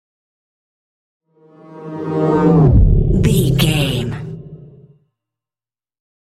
Whoosh deep large
Sound Effects
dark
futuristic
intense